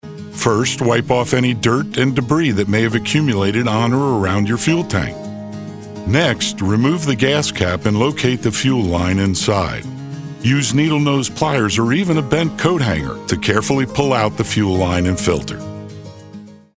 A Uniquely Deep, Resonant and Relatable Voice Over Actor
I have a complete home studio with a RØDE NT1 5th Generation Large-Diaphragm Studio Condenser Microphone, a FocusRite 4th Generation 2i2 Audio Interface.
Instructional-1.mp3